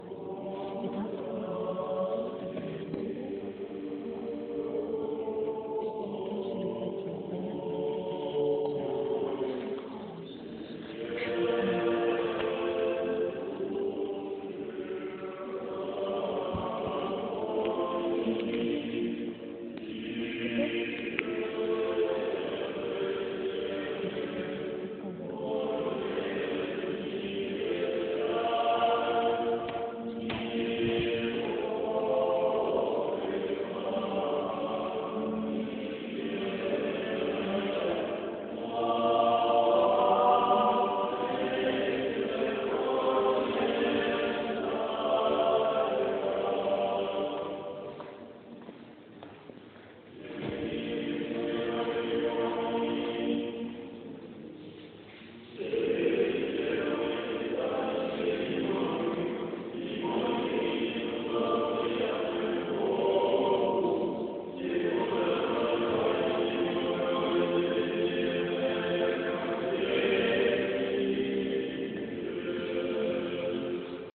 01 Кондак «Со святыми упокой» и богородичен «Тебе и стену и пристанище имамы» в исполнении хора Свято-Донского Старочеркасского мужского монастыря на литургии Дмитровской родительской субботы. Станица Старочеркасская Аксайского р-на Ростовской обл.